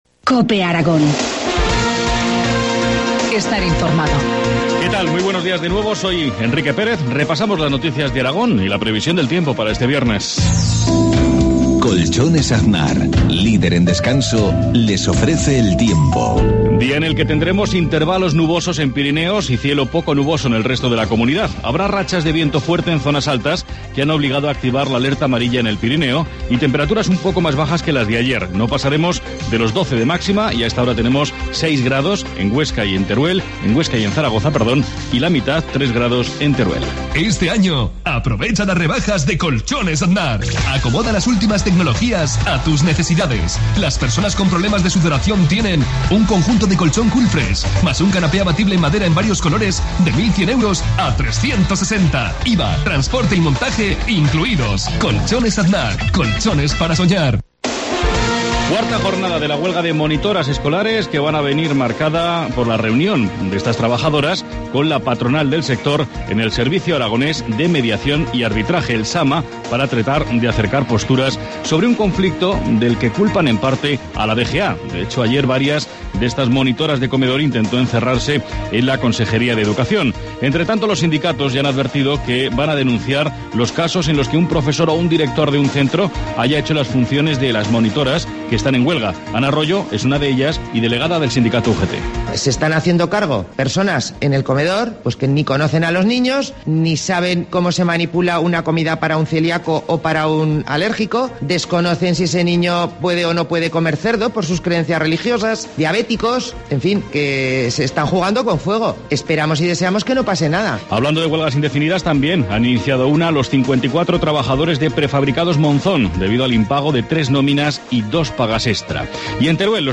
Informativo matinal, viernes 11 de enero, 7.53 horas